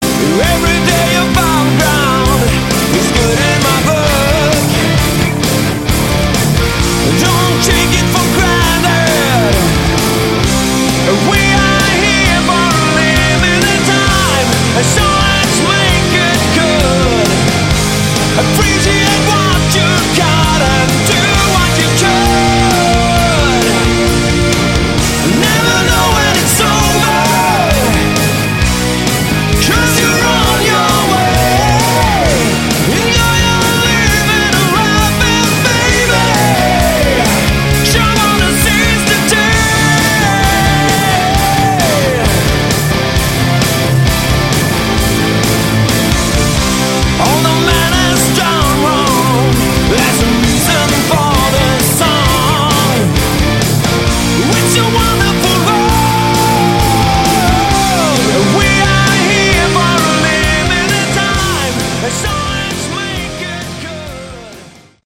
Category: Hard Rock
Guitars
Keyboards
Bass
Drums
Vocals